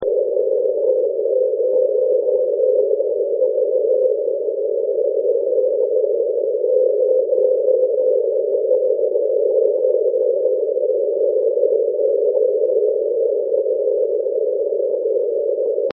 Je to typická ukázka, kterou dokáží přečíst jen "zkušené uši". Ano přesně takto musíte mít nastaveno rádio, pokud něco hledáta v šumu.